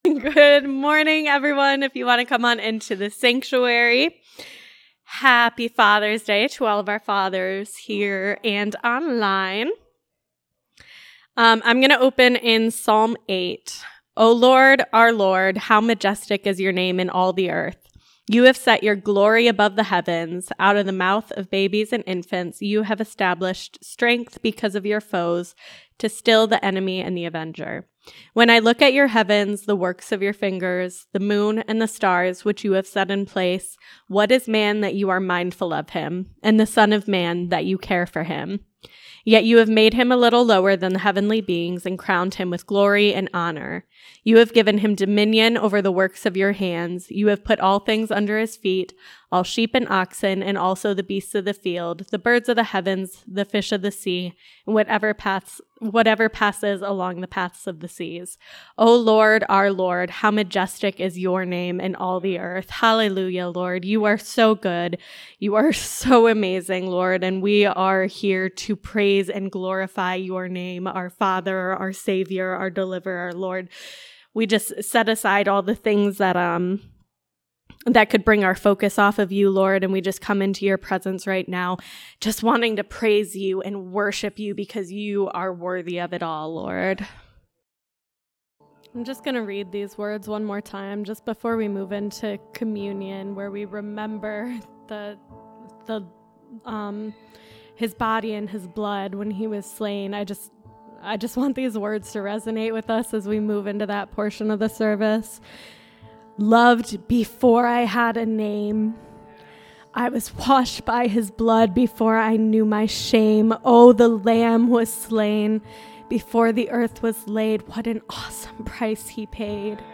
Father’s Day Sunday Service
Service Type: Sunday Service